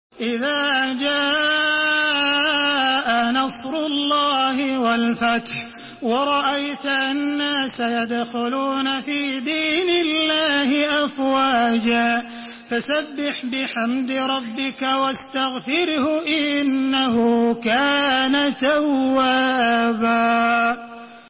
تحميل سورة النصر بصوت تراويح الحرم المكي 1432
مرتل